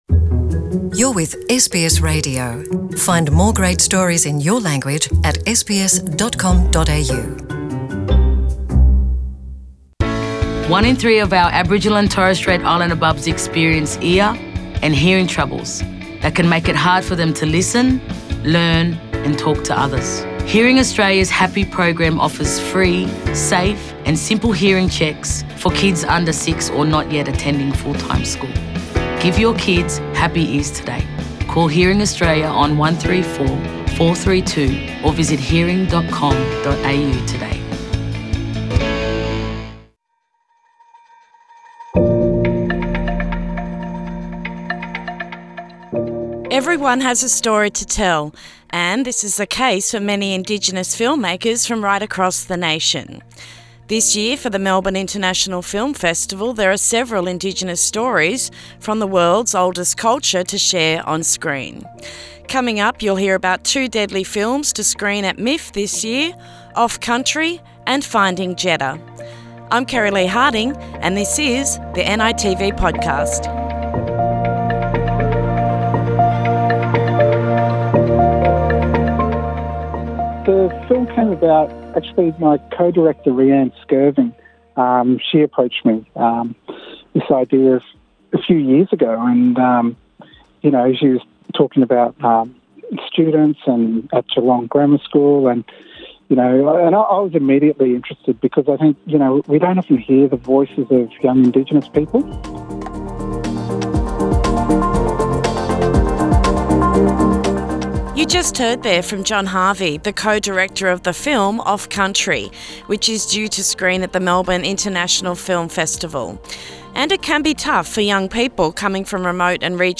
In this episode of the NITV Podcast series we explore some of the Indigenous films screening at the 2021 Melbourne International Film Festival. Interviews with Indigenous filmmakers